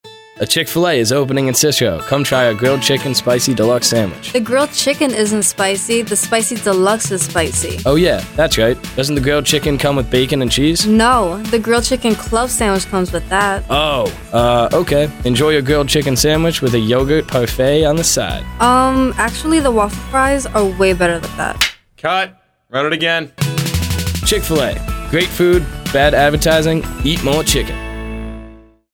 Chick Fil A Radio Spot
chik-fil-a-bad-avertising.mp3